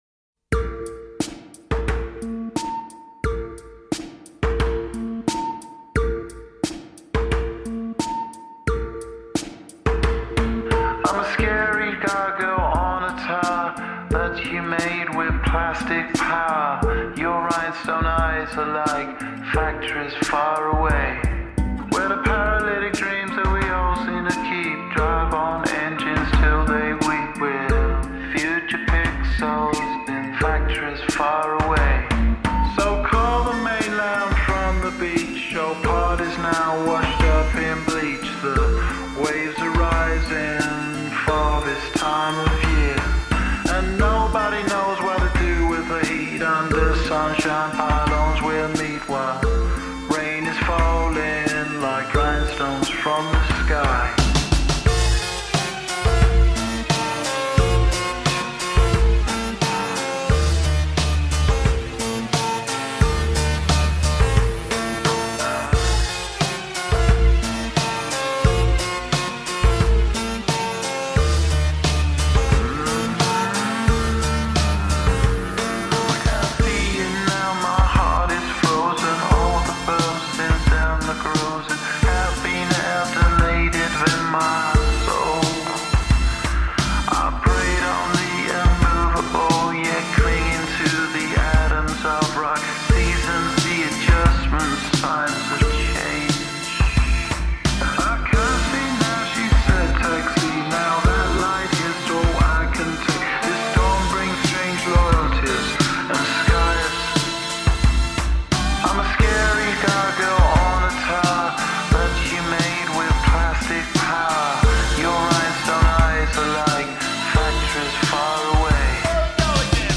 slowed + 8d + reverb